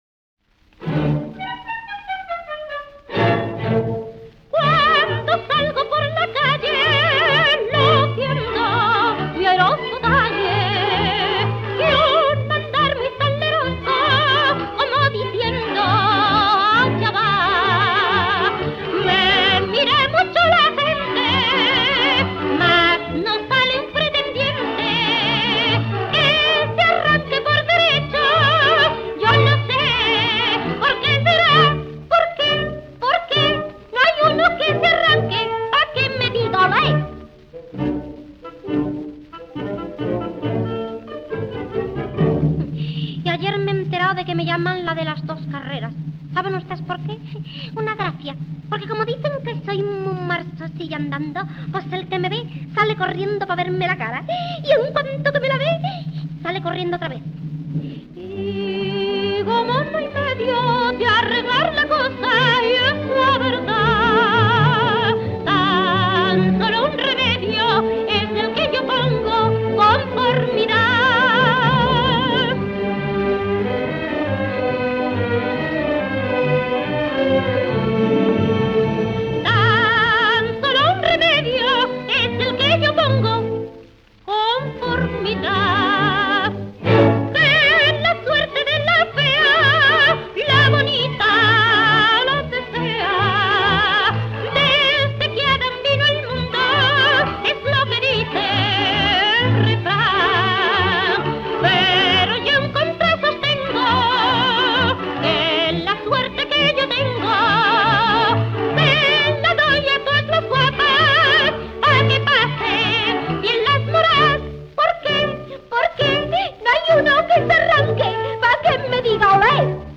pasacalle
78 rpm